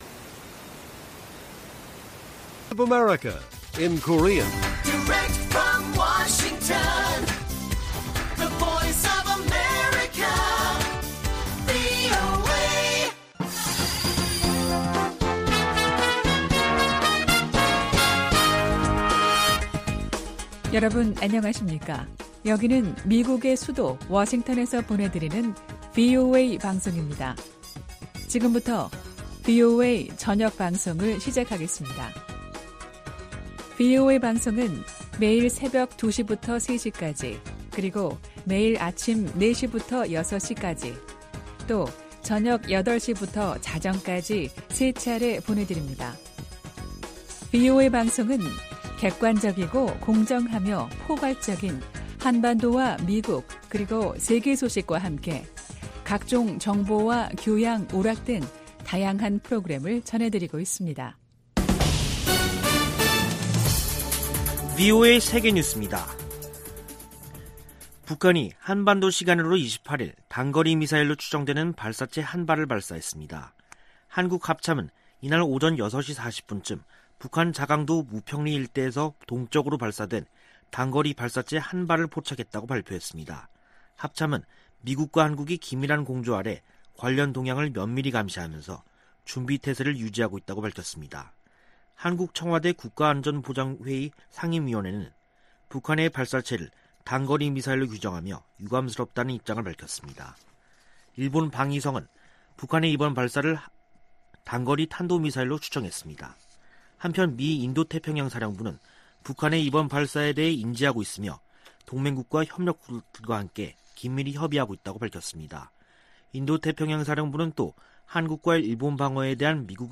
VOA 한국어 간판 뉴스 프로그램 '뉴스 투데이', 2021년 9월 28일 1부 방송입니다. 북한은 김여정 노동당 부부장이 대남 유화 담화를 내놓은 지 사흘 만에 단거리 미사일 추정 발사체를 동해 쪽으로 발사했습니다. 미 국무부는 북한의 발사체 발사를 규탄했습니다. 유엔주재 북한 대사가 미국에 대북 적대시 정책을 철회하라고 요구했습니다.